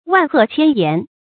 萬壑千巖 注音： ㄨㄢˋ ㄏㄜˋ ㄑㄧㄢ ㄧㄢˊ 讀音讀法： 意思解釋： 形容峰巒、山谷極多。